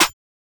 Clap (Hard).wav